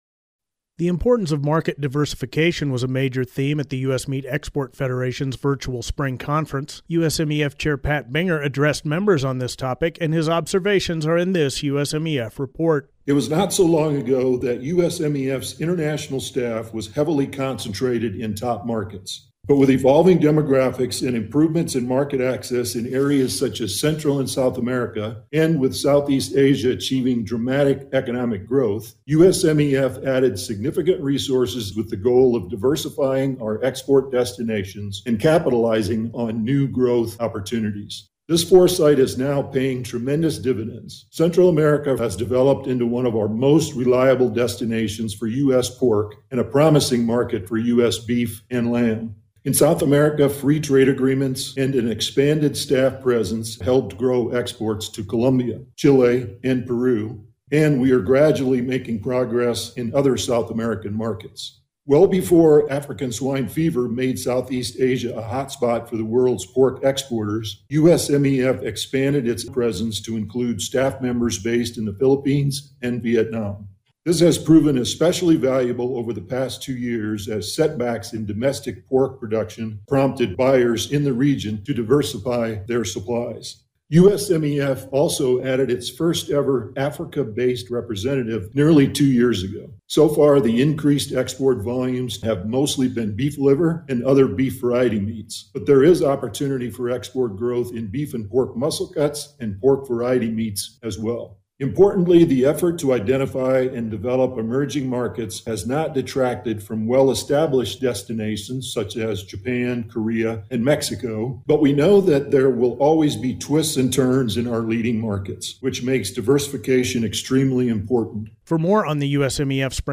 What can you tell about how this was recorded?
The importance of market diversification for U.S. red meat exports was a key point of emphasis at the U.S. Meat Export Federation Spring Conference, which was held in a virtual format May 26-27, 2021.